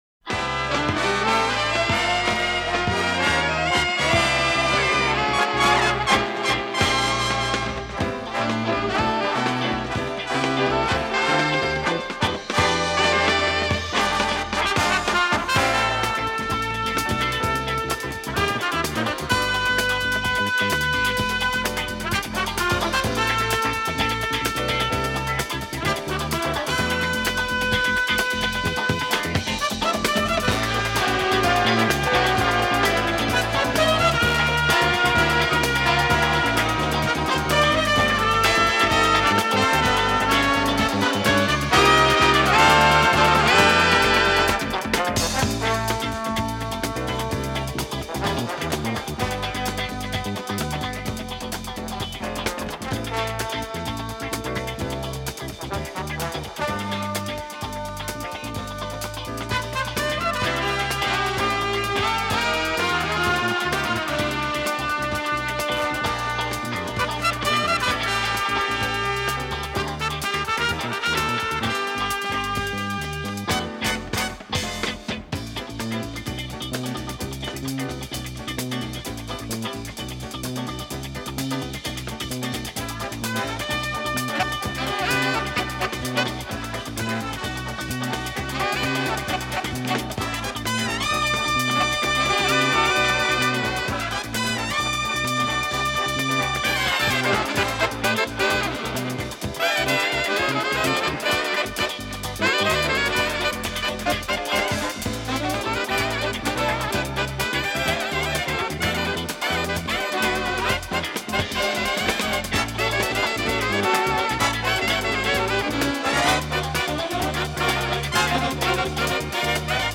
Audiokasete
Instrumentāls skaņdarbs